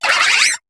Cri de Mateloutre dans Pokémon HOME.